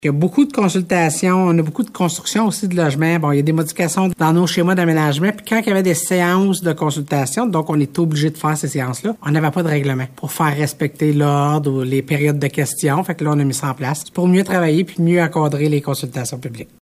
La préfète de la Vallée-de-la-Gatineau, Chantal Lamarche, explique :